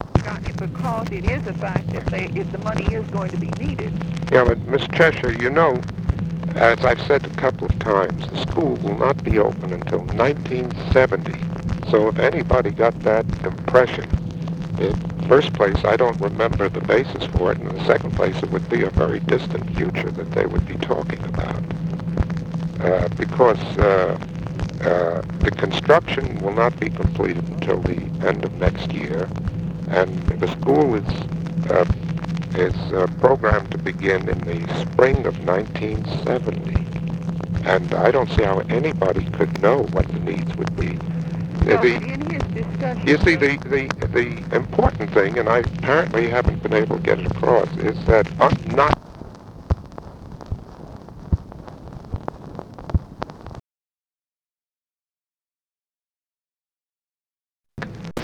Conversation with MAXINE CHESHIRE
Secret White House Tapes | Lyndon B. Johnson Presidency